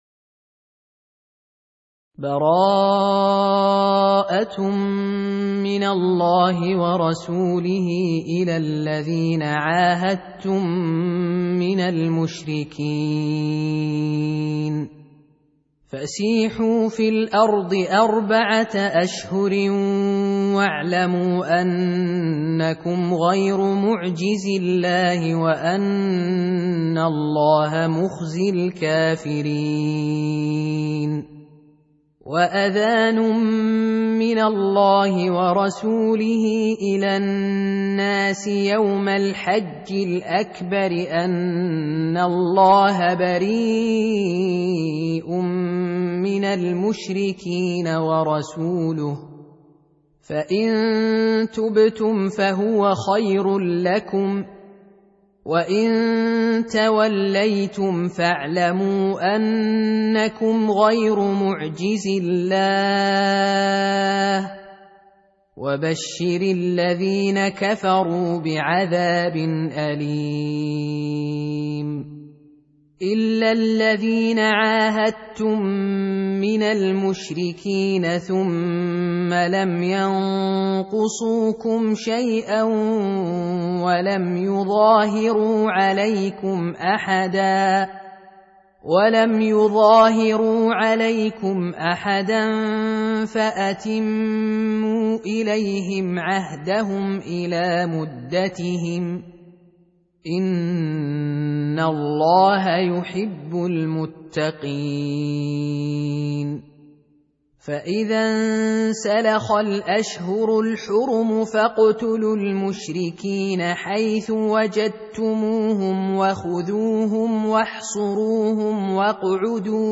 Audio Quran Tarteel Recitation
Surah Repeating تكرار السورة Download Surah حمّل السورة Reciting Murattalah Audio for 9. Surah At-Taubah سورة التوبة N.B *Surah Excludes Al-Basmalah Reciters Sequents تتابع التلاوات Reciters Repeats تكرار التلاوات